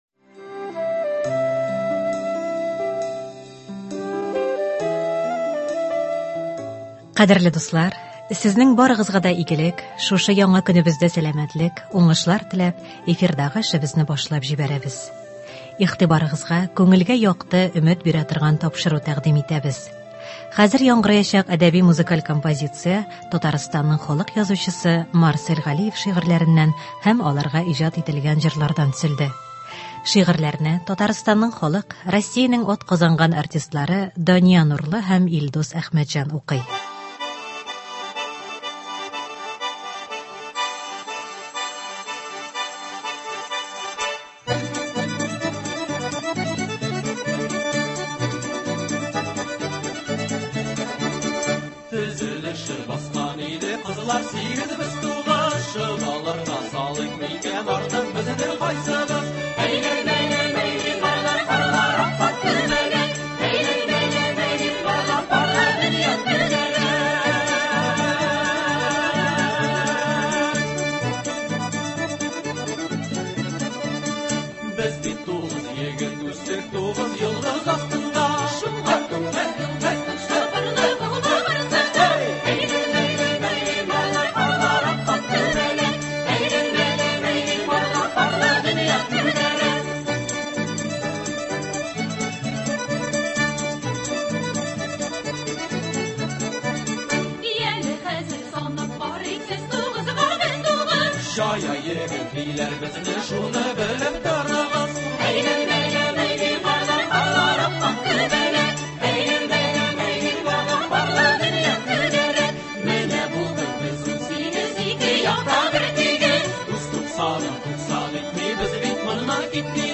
Әдәби-музыкаль композиция (24.12.22)
Игътибарыгызга күңелгә якты, өмет бирә торган тапшыру тәкъдим итәбез. Хәзер яңгыраячак әдәби-музыкаль композиция Татарстанның халык язучысы Марсель Галиев шигырьләреннән һәм аларга иҗат ителгән җырлардан төзелде.